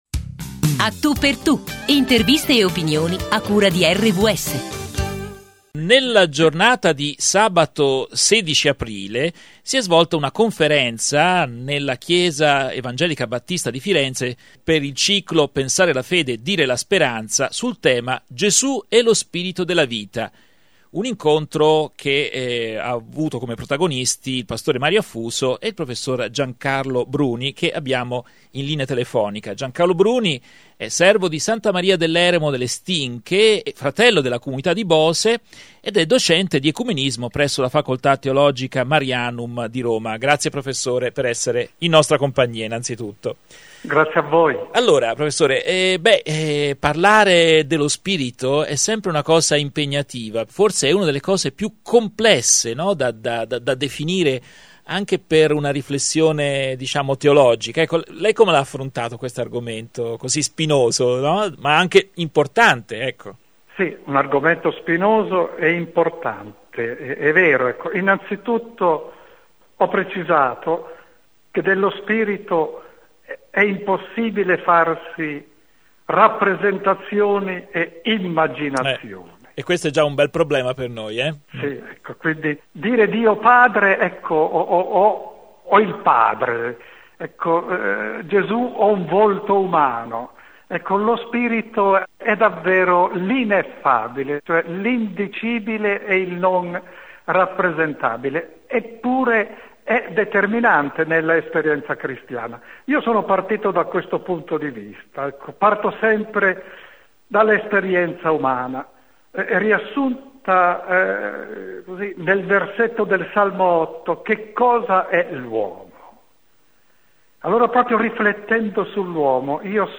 L’intervista prende le mosse dal tema di una conferenza